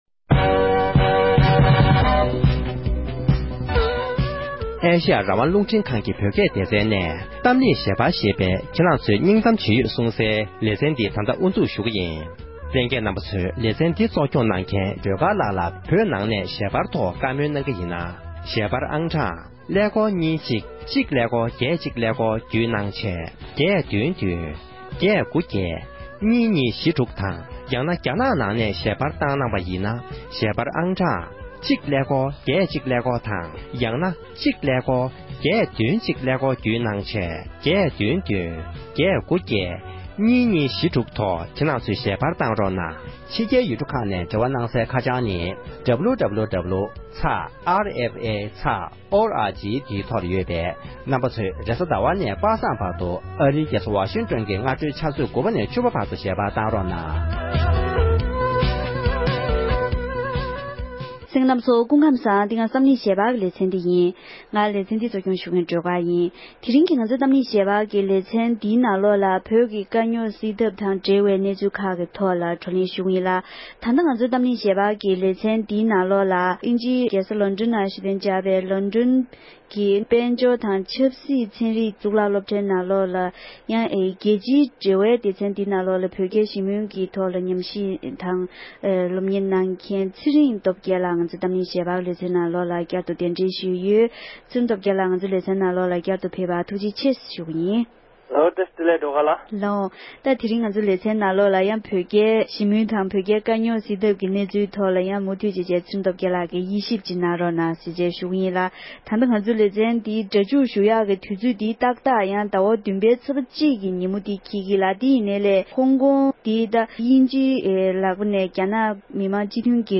༄༅༎དེ་རིང་གི་གཏམ་གླེང་ཞལ་པར་གྱི་ལེ་ཚན་ནང་དུ་བོད་རྒྱའི་ཞི་མོལ་ཐེངས་བདུན་པའི་སྐོར་ལ་དབྱེ་ཞིབ་དང་བོད་ཀྱི་དཀའ་རྙོག་དང་འབྲེལ་བའི་གནས་ཚུལ་ཁག་གི་ཐོག་བགྲོ་གླེང་ཞུས་པ་ཞིག་གསན་རོགས་གནང༌༎